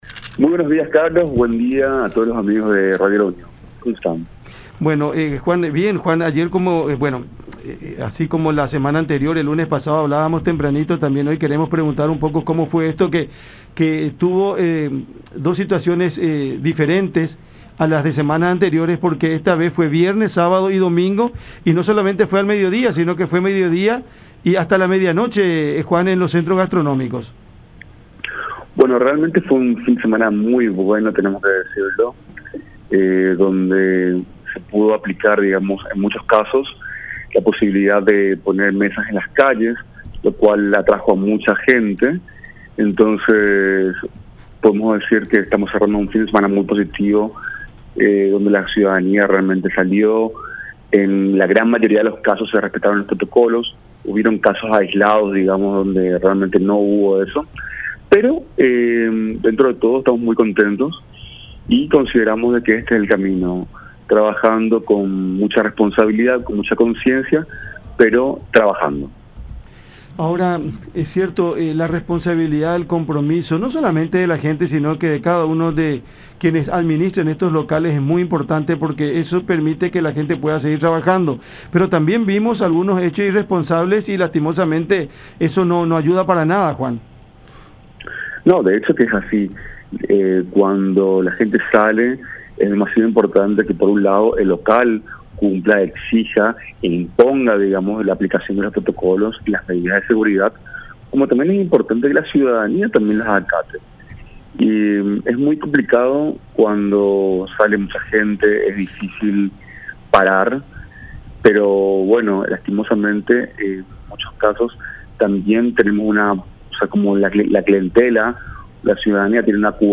en conversación con Cada Mañana a través de La Unión.